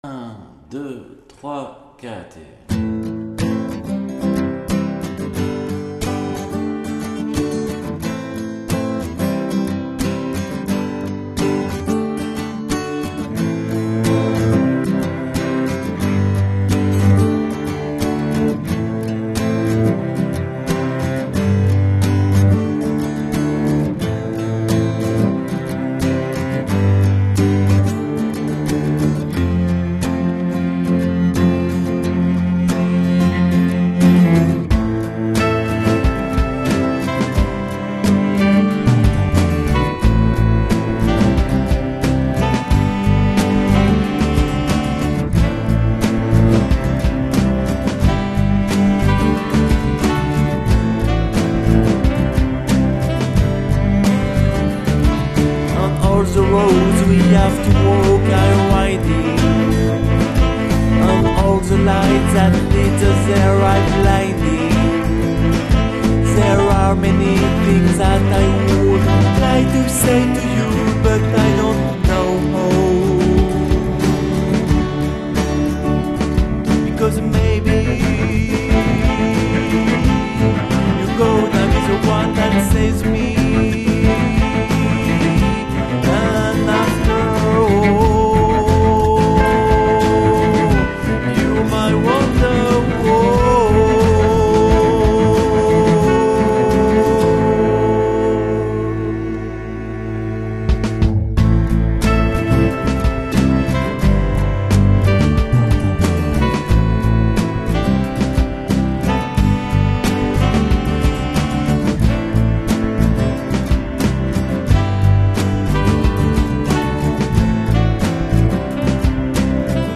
sans mélodie (nouvelle tona + sax + voix harmonisée